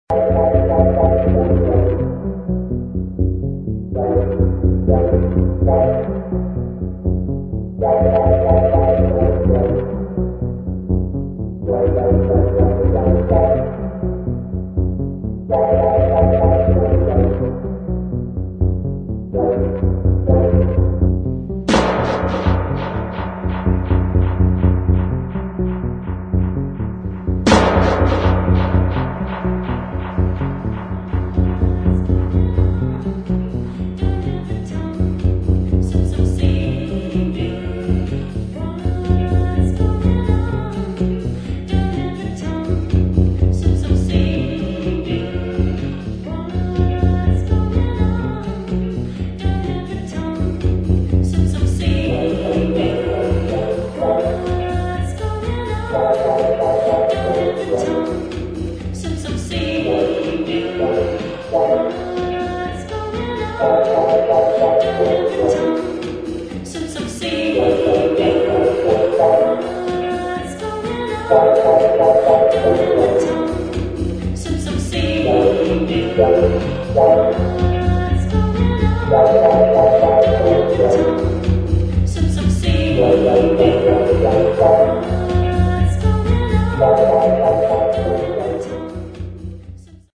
[ DEEP HOUSE / TECHNO ]